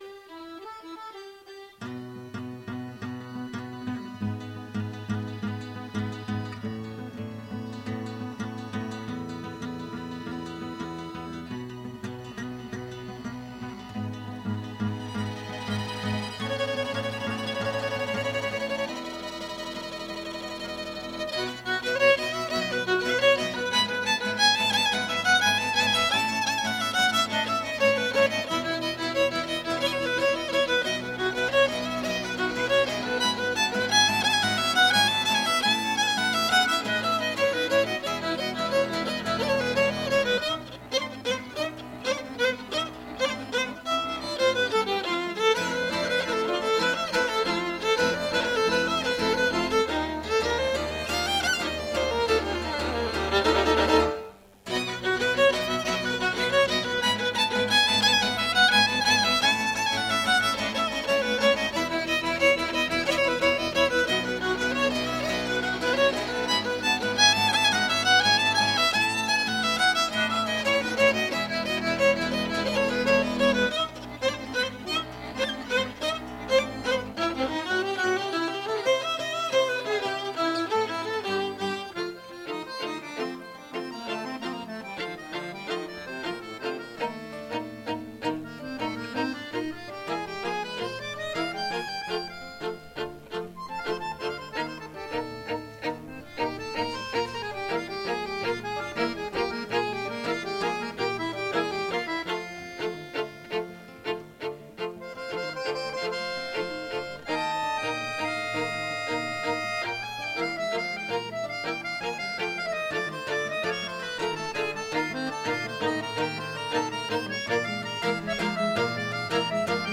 hirukote Galiziarraren zuzenekoa, benetan gozagarria.